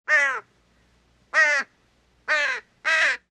На этой странице собраны разнообразные звуки ворон и воронов: от одиночных карканий до хоровых перекличек.
Крик вороны — второй вариант